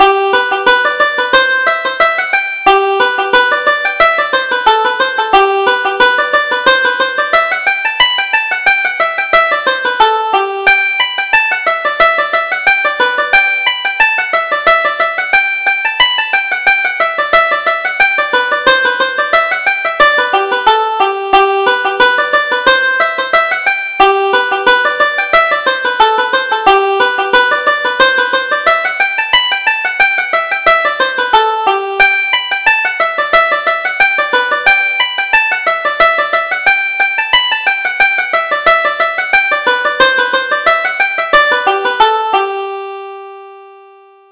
Reel: Comely Jane Downing